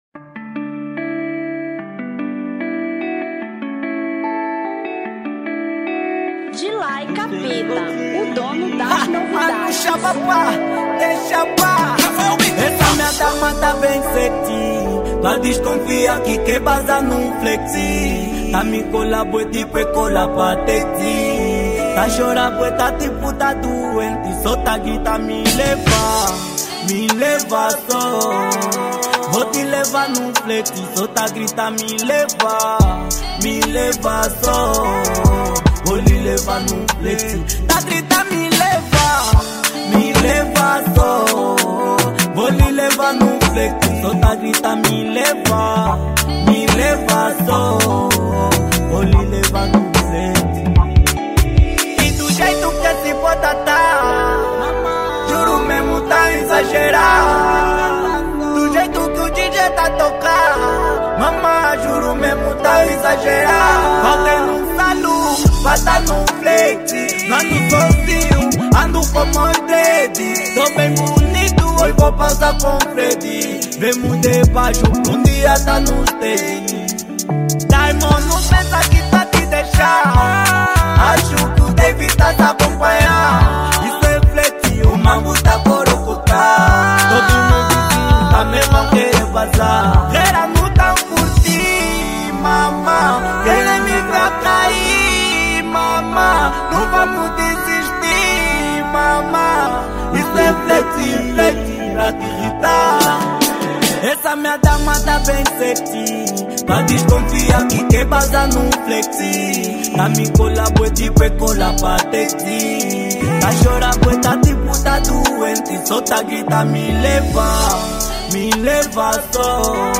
Kuduro 2024